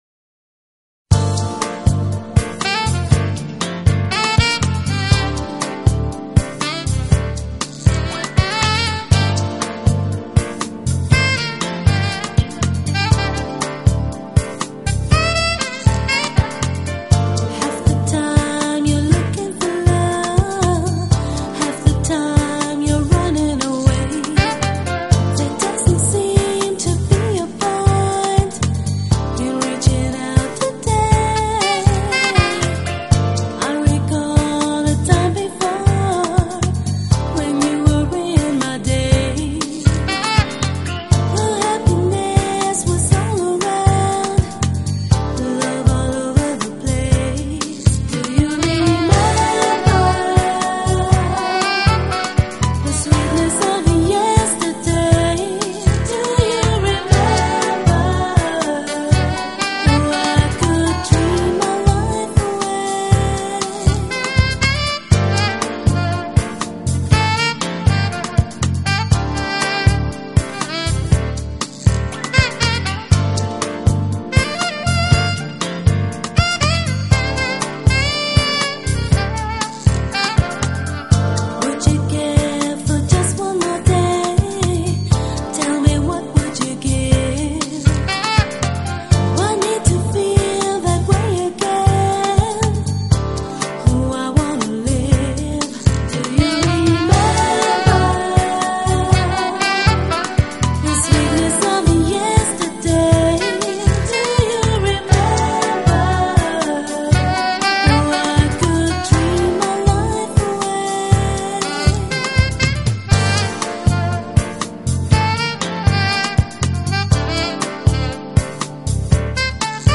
始录制唱片，由于善于把握时尚元素，将Smooth Jazz与电子、舞曲风格完美结
旋律轻柔流畅，器乐创新搭配，节奏舒缓时尚，魅力